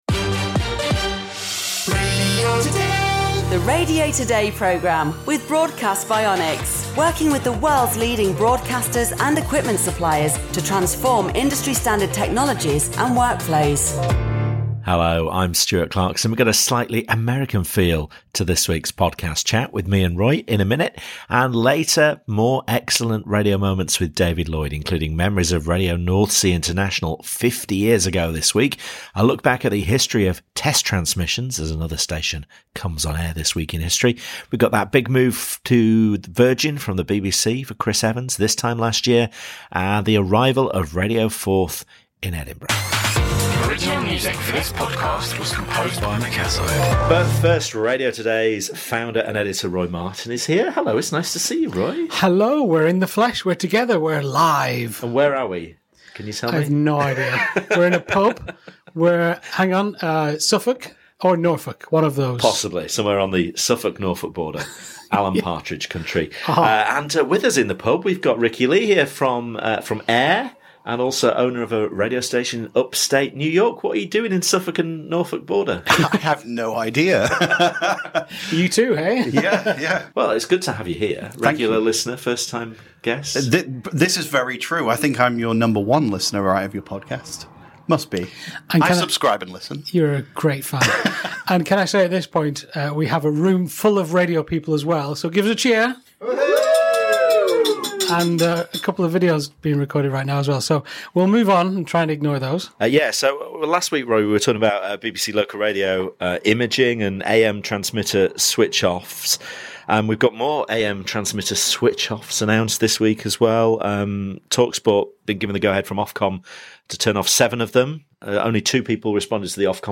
in the pub to talk about the week’s big stories, with an American perspective on AM transmission, in-car listening and the removal of local programming in favour of network shows.